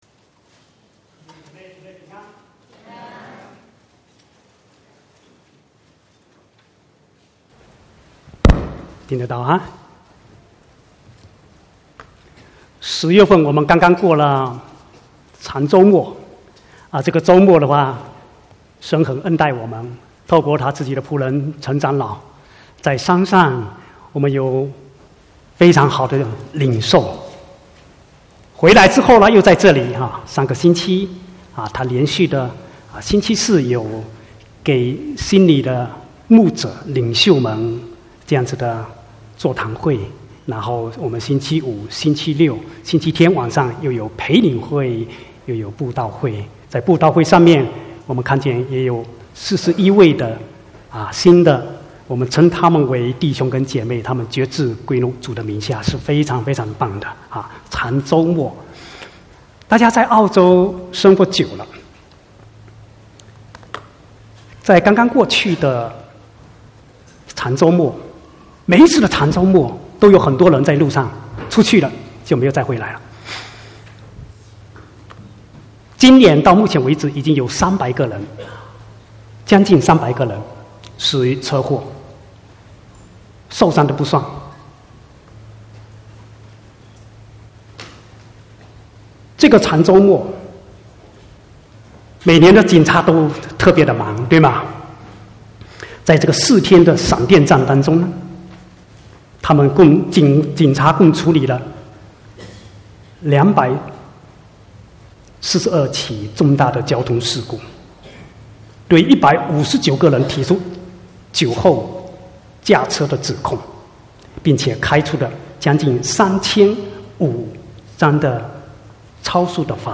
20/10/2019 國語堂講道